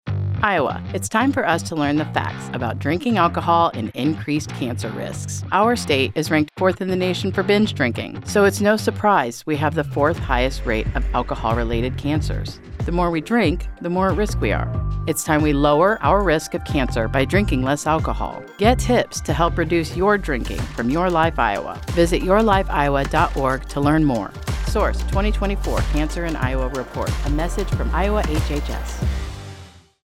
:30 Radio Spot | Cancer & Alcohol | Straight Facts | Female